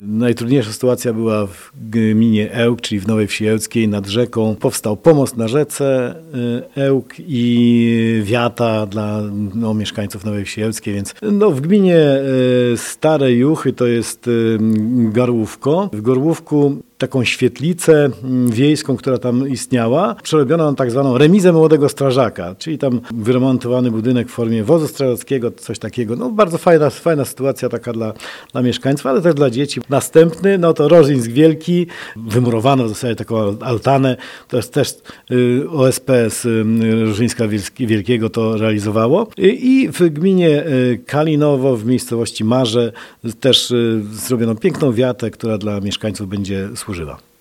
– Wszystkie projekty już zostały zrealizowane – mówi Marek Chojnowski, starosta powiatu ełckiego.